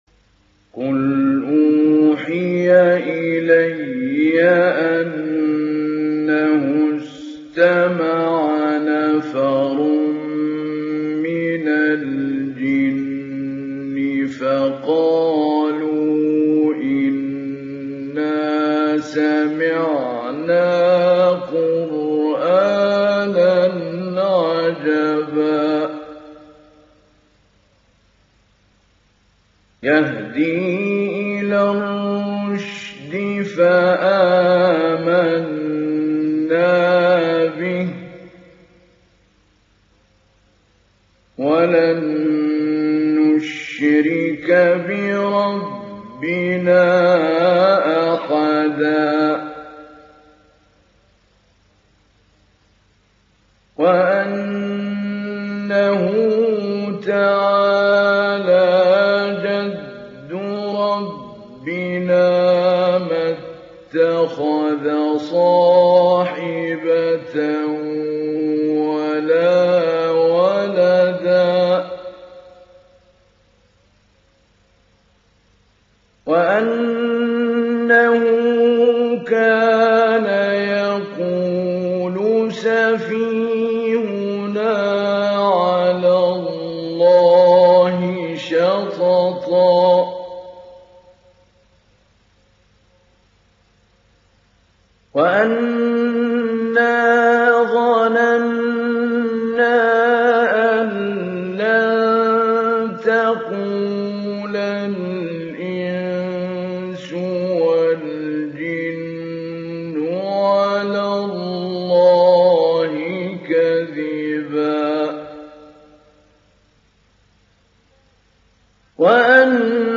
دانلود سوره الجن mp3 محمود علي البنا مجود روایت حفص از عاصم, قرآن را دانلود کنید و گوش کن mp3 ، لینک مستقیم کامل
دانلود سوره الجن محمود علي البنا مجود